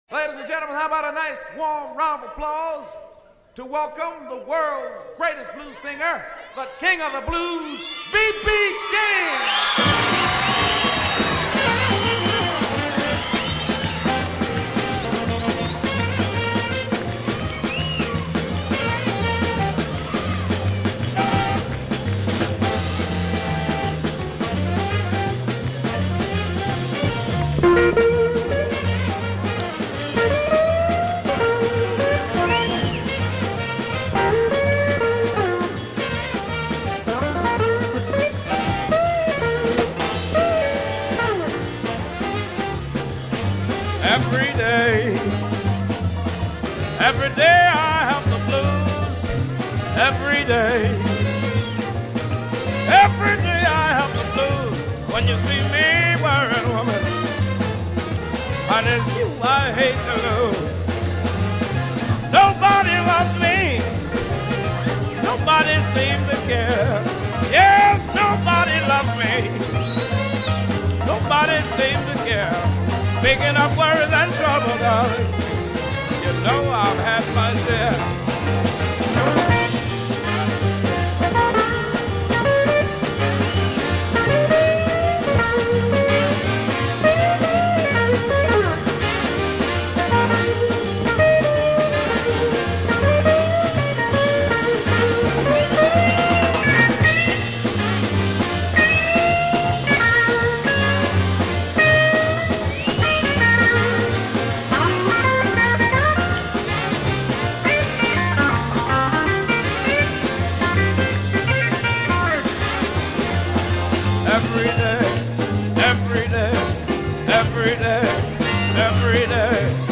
Blues / Rhythm & Blues